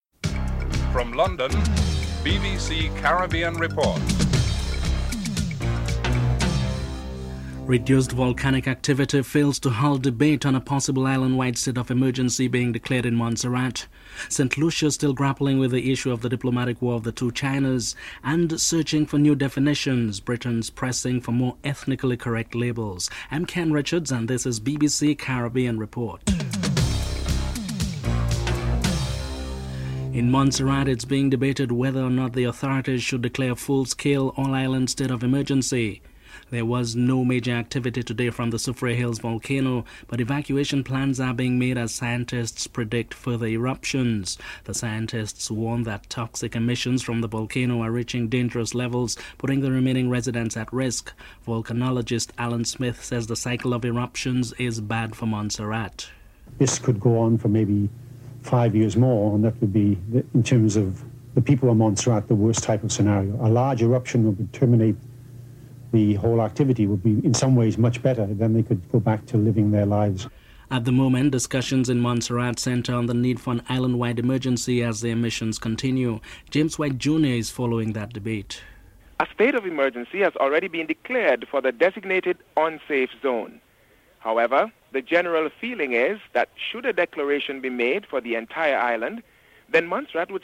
The British Broadcasting Corporation
1. Headlines (00:00-00:28)
George Odlum, Foreign Minister is interviewed